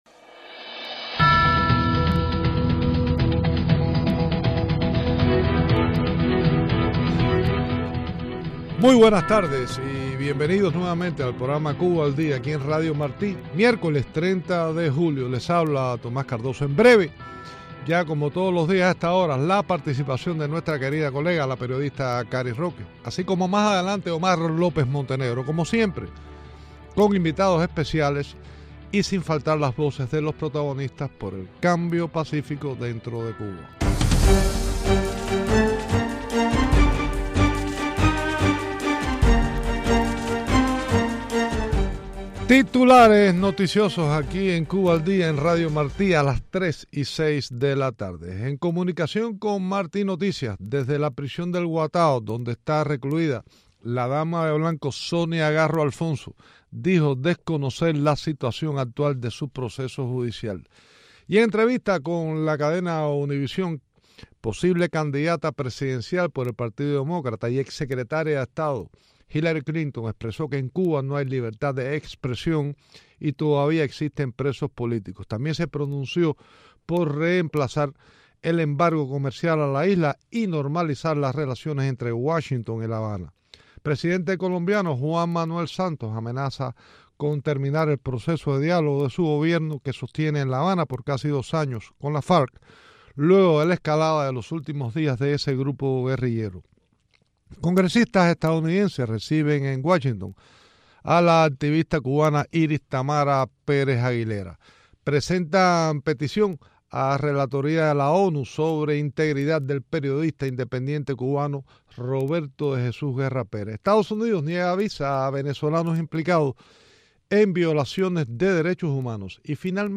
Entrevistas con embajdor Myles Frechette